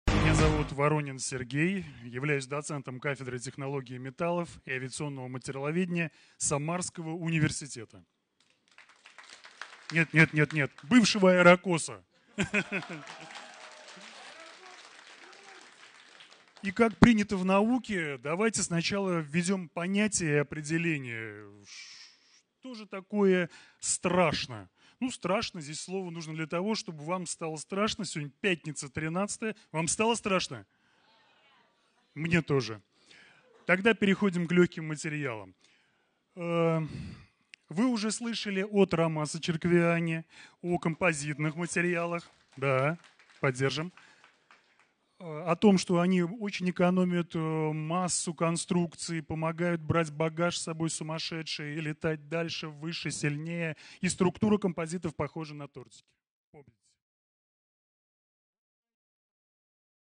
Аудиокнига Страшно лёгкие материалы | Библиотека аудиокниг